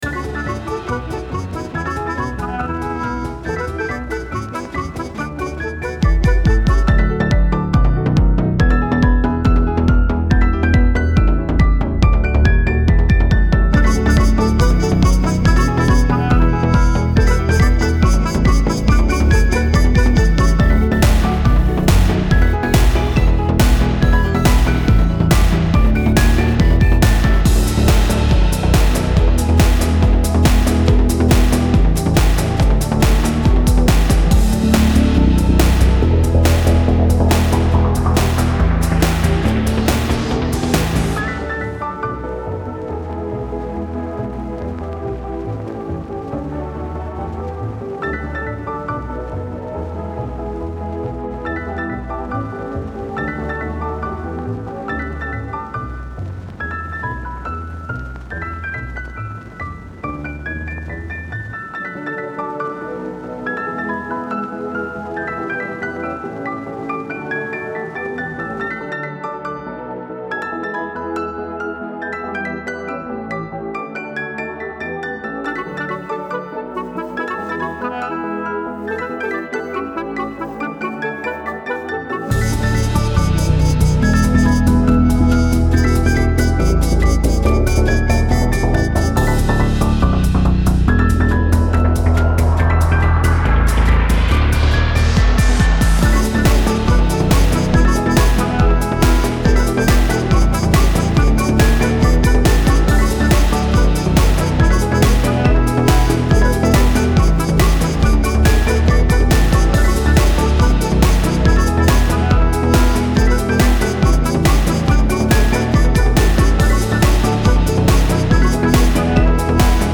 Style Style EDM/Electronic, Oldies
Mood Mood Bright, Driving
Featured Featured Bass, Brass, Drums +2 more
BPM BPM 140